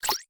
menu-back-click.ogg